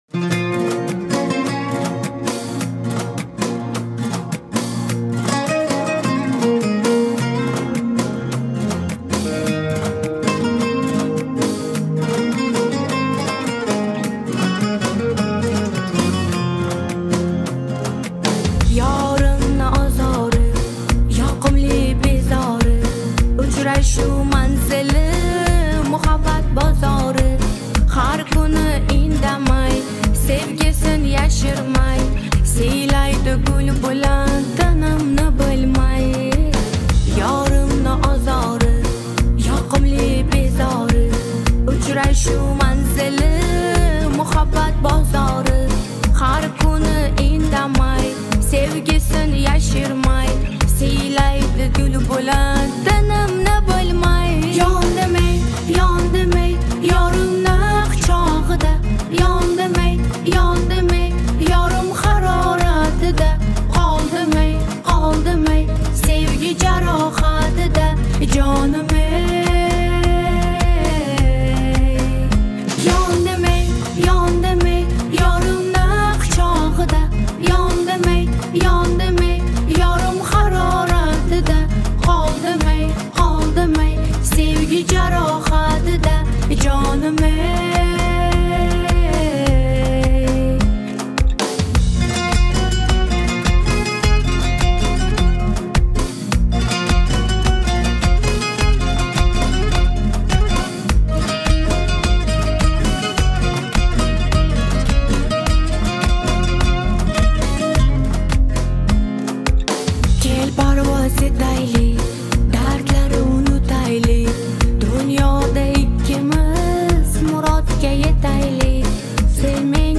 Категория: Узбекские